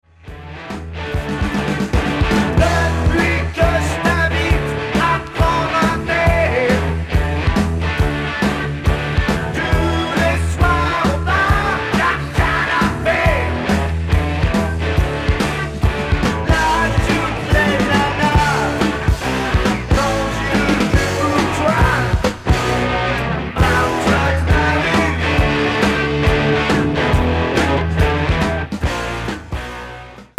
Rock Unique 45t